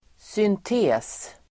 Uttal: [synt'e:s]